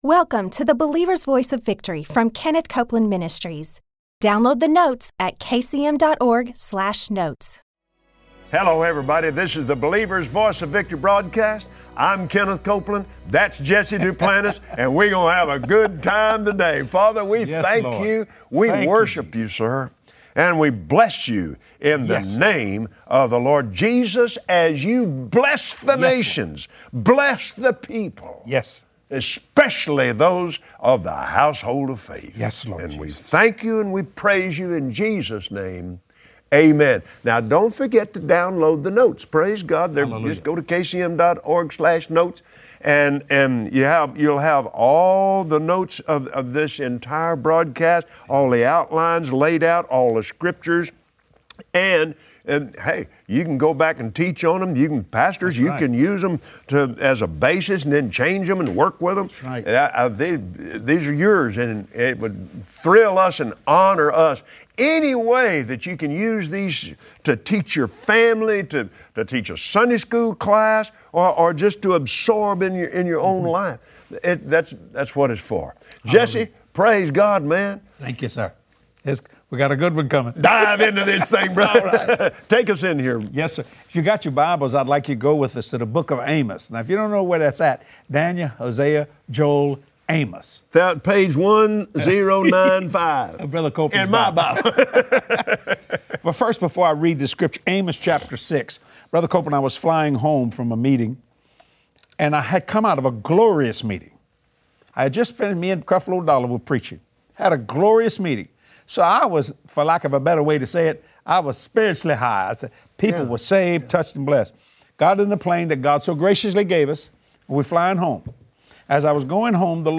Today, on the Believer’s Voice of Victory, join Kenneth Copeland and his special guest Jesse Duplantis for insightful teaching to overcome boundaries and walk in victory every day.